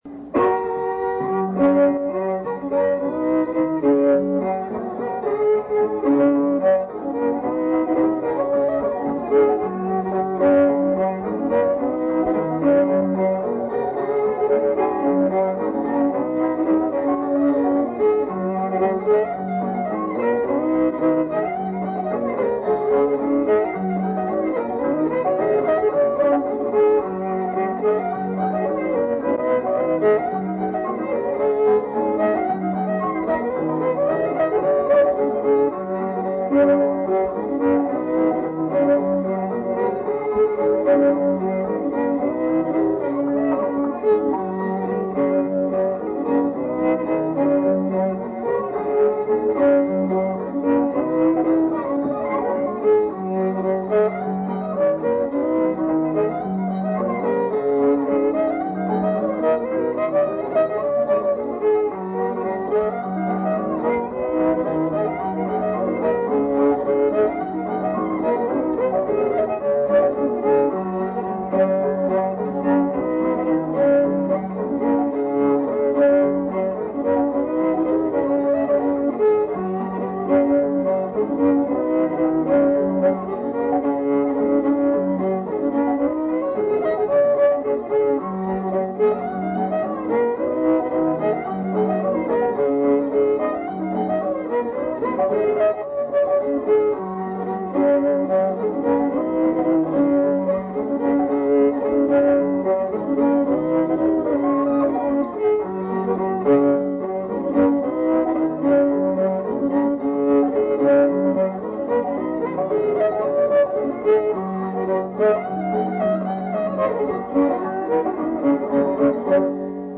Key of G, standard tuning on all three recordings.
fiddle), accompanied by unnamed musicians
Anderson County, Kentucky, 1973